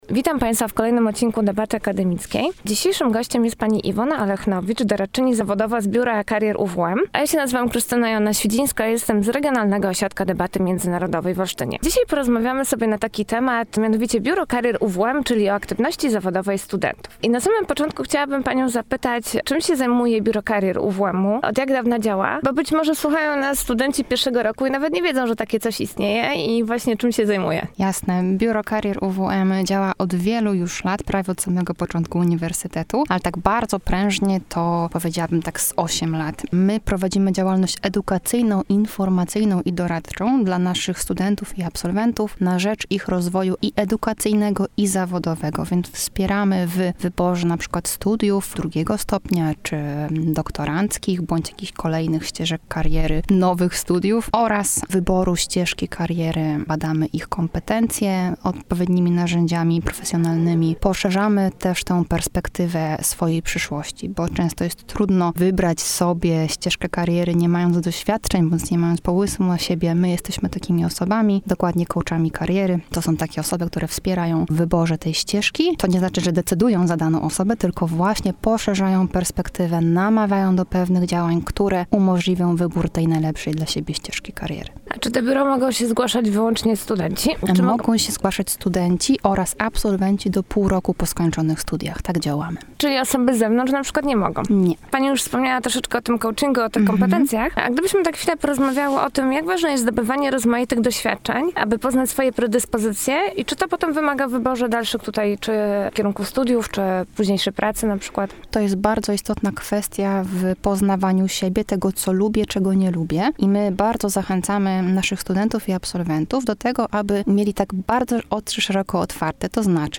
gościła w studiu Radia UWM FM